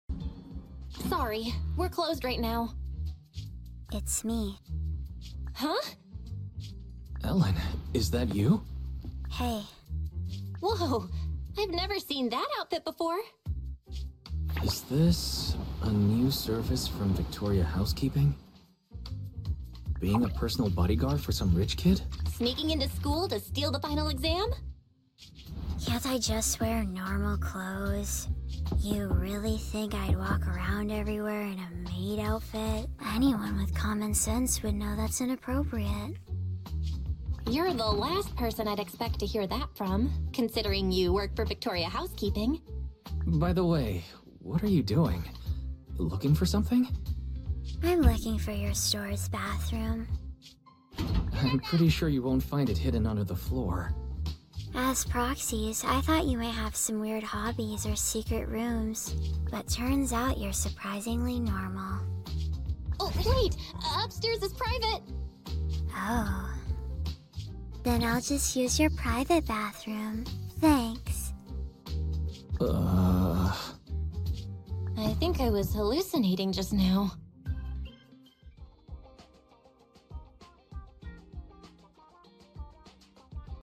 Kinda Hate This Voice Over Sound Effects Free Download